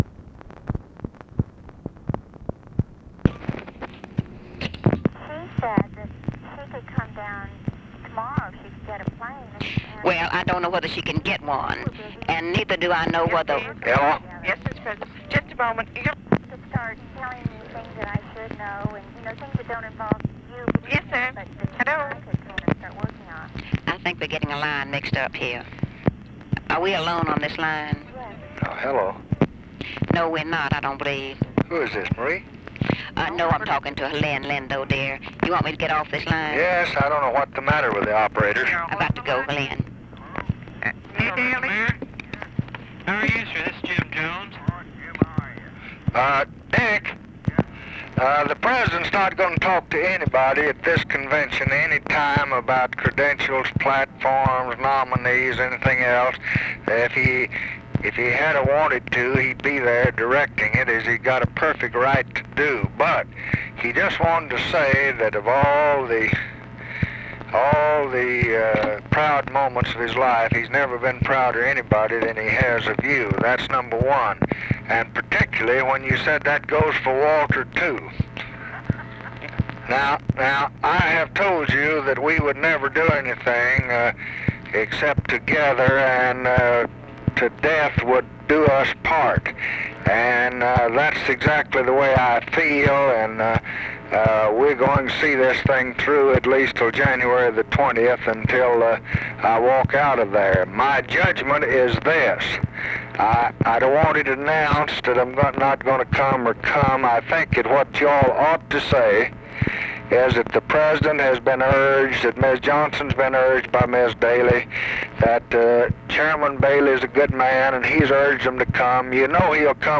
Conversation with RICHARD DALEY, LADY BIRD JOHNSON and JIM JONES, August 27, 1968
Secret White House Tapes